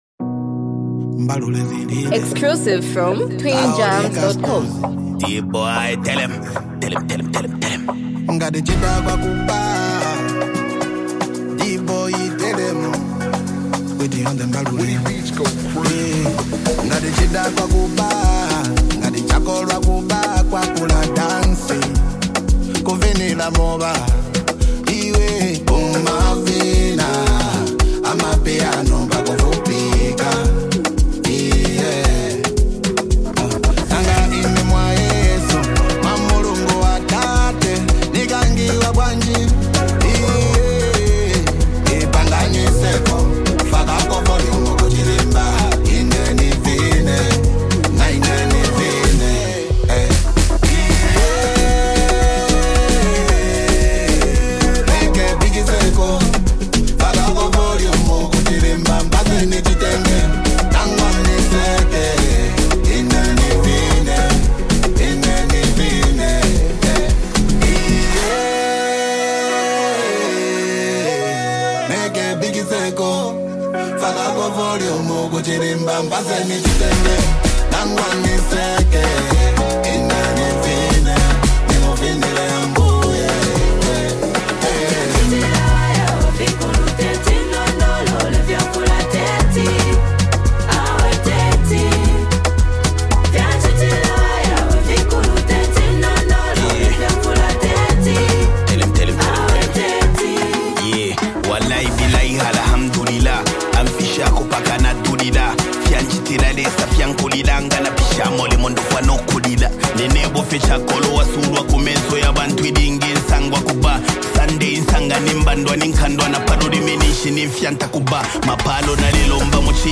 a playful, upbeat song